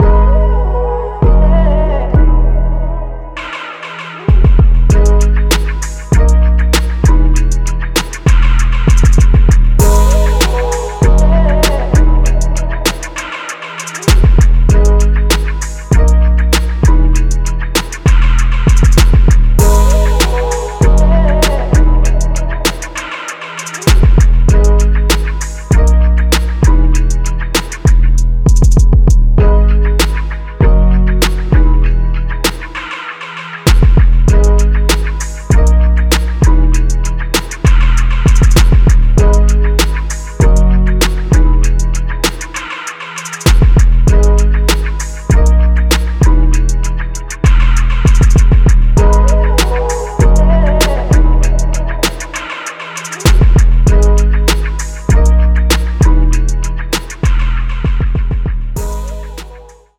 Copyright Free Hip Hop Instrumentals